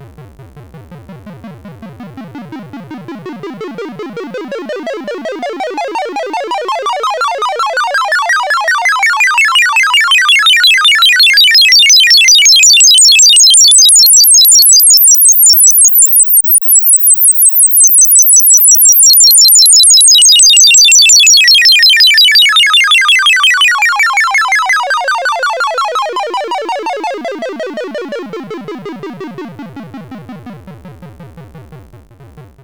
RISE + FALL.wav